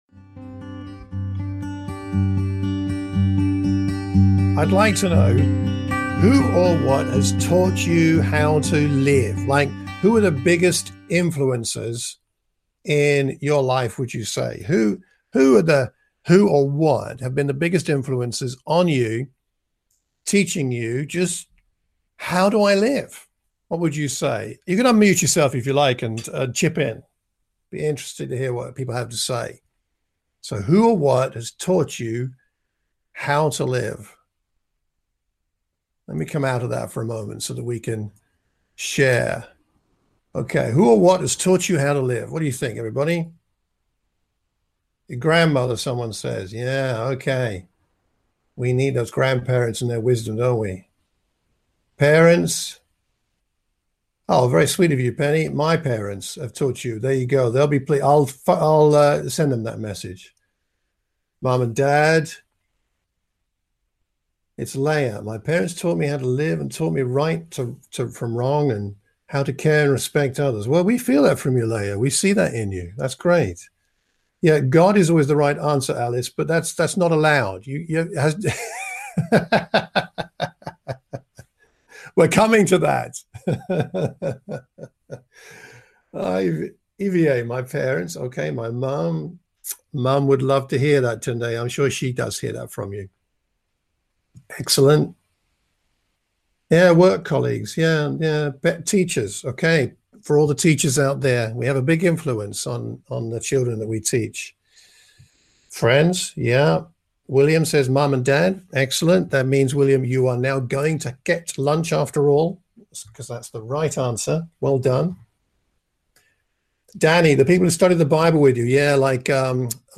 A sermon for the Watford church of Christ. We look at how God's love was released in the world. Jesus shows us how to trust, die and rise.